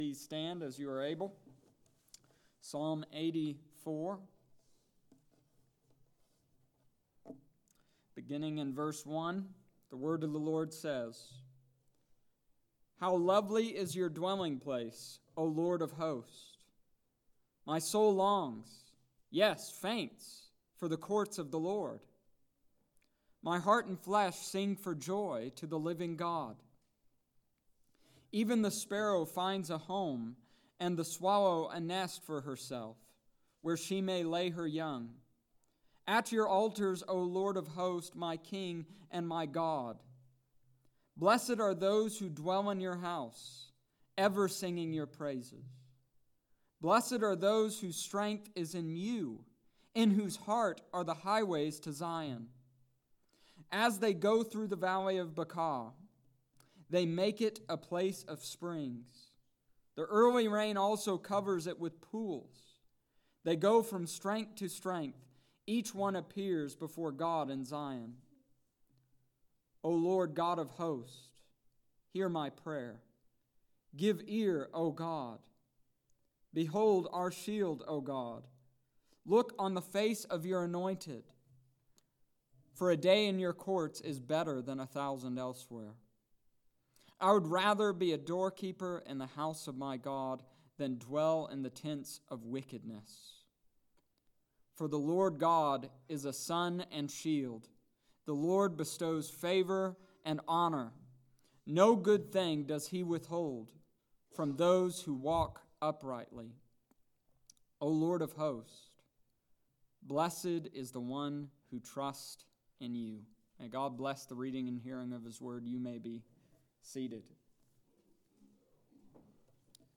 Psalm Chapter 84 verses 1-12 Wednesday Night Service October 16th,2019